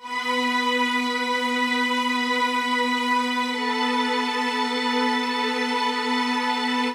synth01.wav